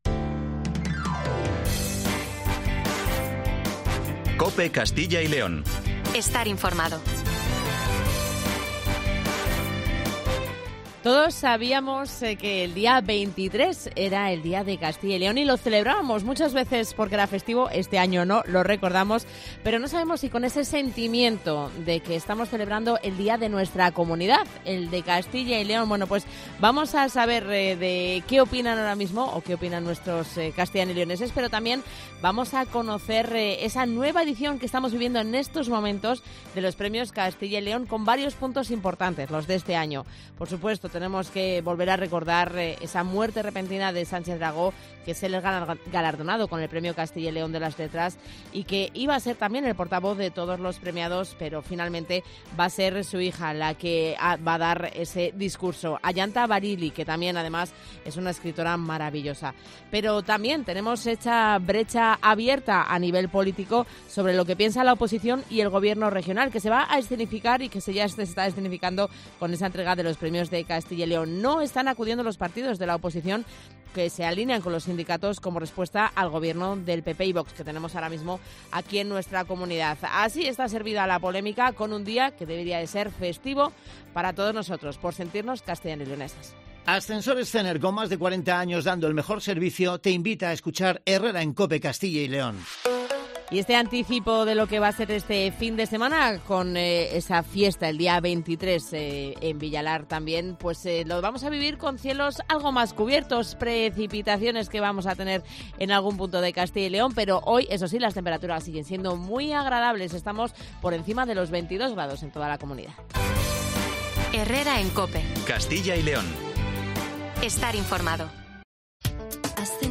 Nos acercamos al Auditorio Miguel Delibes donde se está celebrando la Gala de entrega de los Premios Castilla y León.